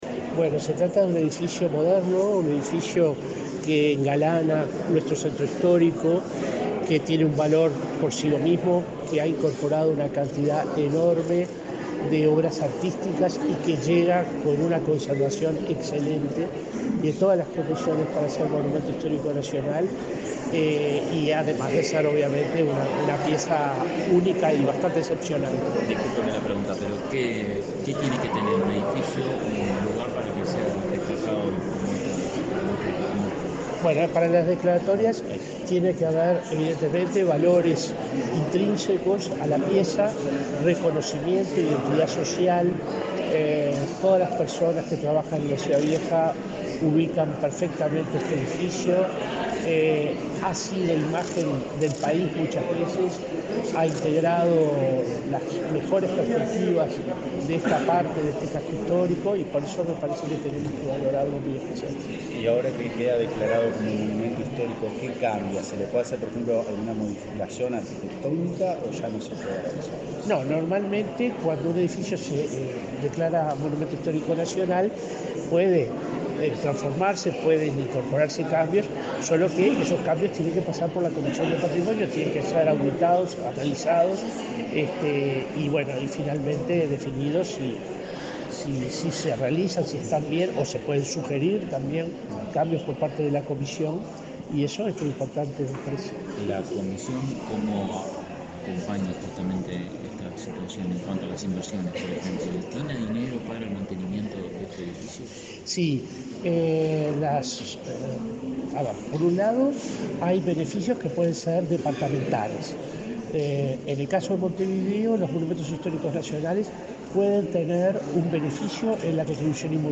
Declaraciones a la prensa del responsable de la Comisión del Patrimonio Cultural de la Nación
El Ministerio de Educación y Cultura, a través de la Comisión del Patrimonio Cultural de la Nación, declaró Monumento Histórico Nacional al edificio Artigas, ubicado en la intersección de las calles Rincón y Treinta y Tres, en el barrio Ciudad Vieja, de Montevideo. Luego, el responsable de la comisión, William Rey, dialogó con la prensa.